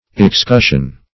Search Result for " excussion" : The Collaborative International Dictionary of English v.0.48: Excussion \Ex*cus"sion\, n. [L. excussio a shaking down; LL., a threshing of corn: cf. F. excussion.]